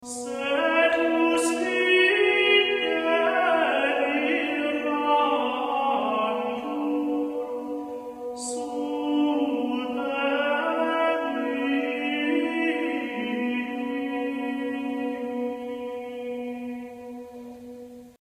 The same melody is sung by two people, but each starts on a different note. Thus, the notes parallel each other, usually with the second voice a fourth or fifth below the first voice.
Organum - Resonemus hoc natali
Organum.mp3